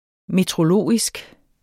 Udtale [ metʁoˈloˀisg ]